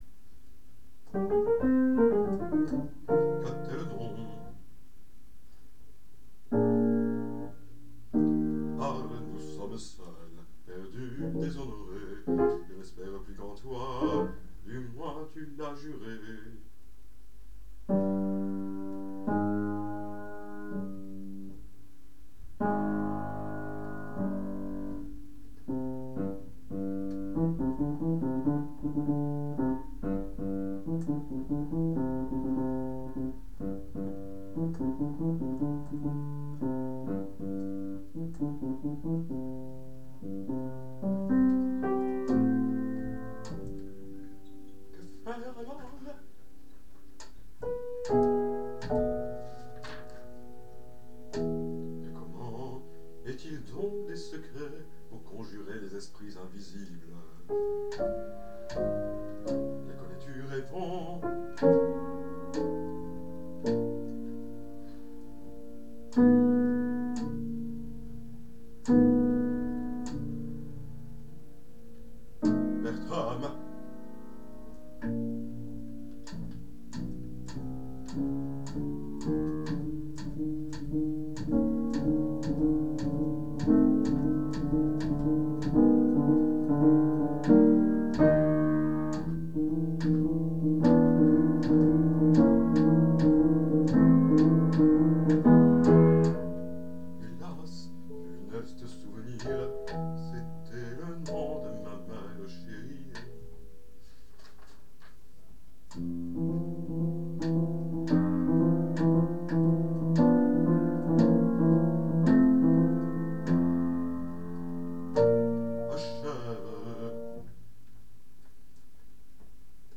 J'ai enregistré simultanément les répliques de Robert (beaucoup manquent cependant dans le duo patriotique), mais inhabituellement à l'octave inférieure, étant tout à fait aphone ces jours-ci. La pédale grinçante est elle en grande forme.